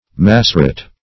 Massoret \Mas"so*ret\, n.
massoret.mp3